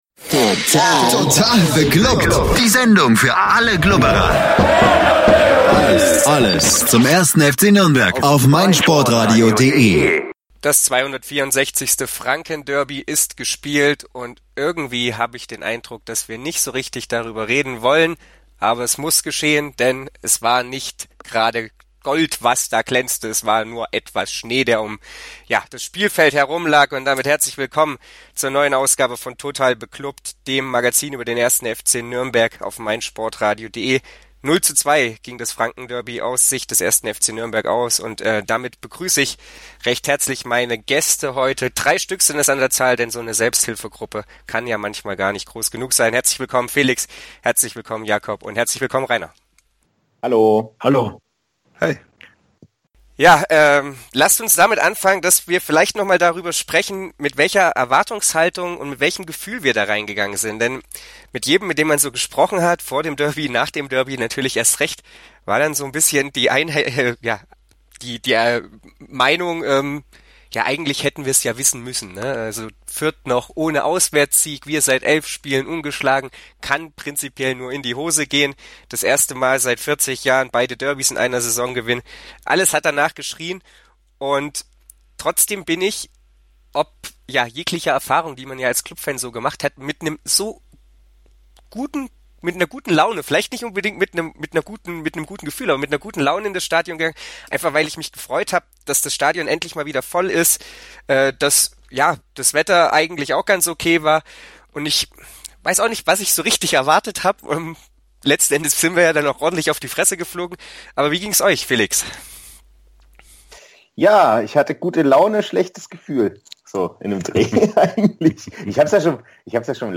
diskutiert mit seinen Gästen